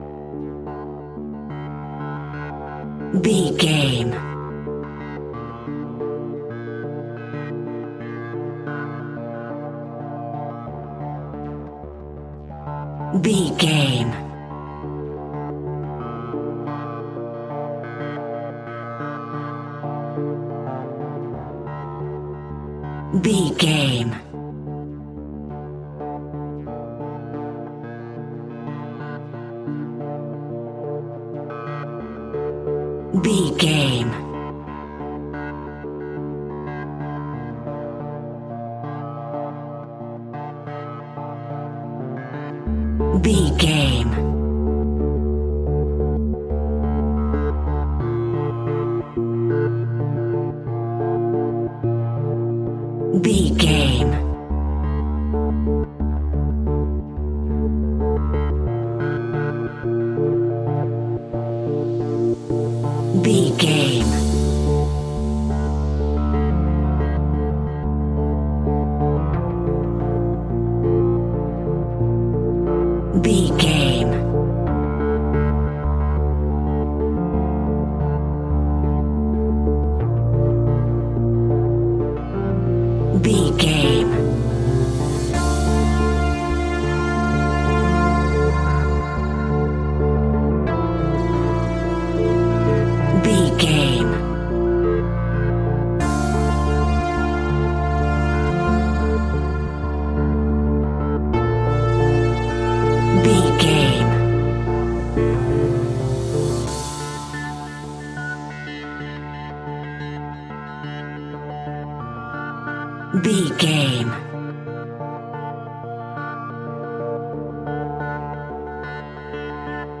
In-crescendo
Thriller
Aeolian/Minor
D
scary
ominous
dark
eerie
synthesiser
drums
percussion
ticking
electronic music